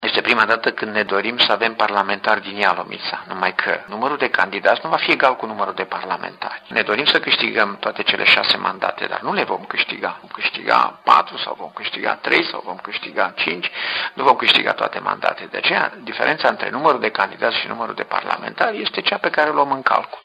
Liderul PSD Ialomiţa, SILVIAN CIUPERCĂ spune că se va merge pe varianta cu 5 candidaţi din Ialomiţa şi unul de la centru: